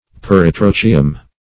Search Result for " peritrochium" : The Collaborative International Dictionary of English v.0.48: Peritrochium \Per`i*tro"chi*um\, n. [NL., fr. Gr.